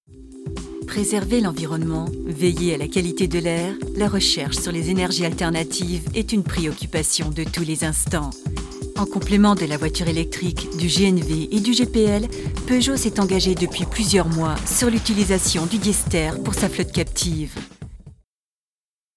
Voix médium, séduisante, s'adaptant à tous vos projets, Home studio Pro, travail sérieux, livraison rapide.
Sprechprobe: Industrie (Muttersprache):
French native female voice, warm, elegant, seduisant, accent.